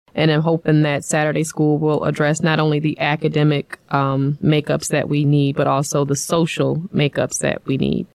Board President Ti’Anna Harrison says.